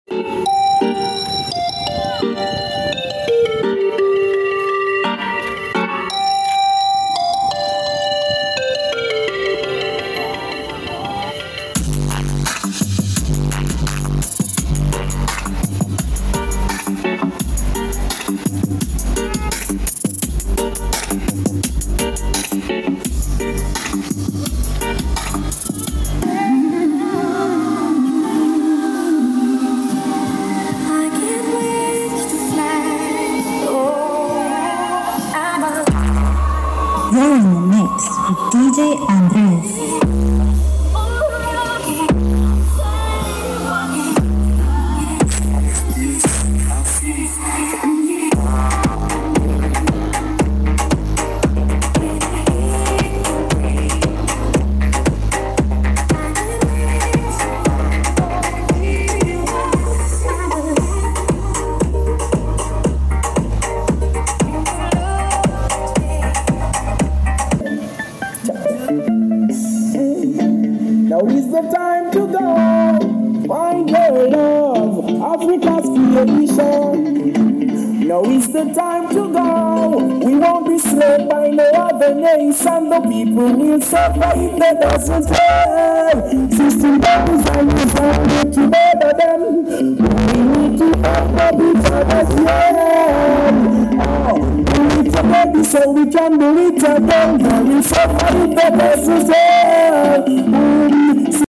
malabar stadium Trinidad sound off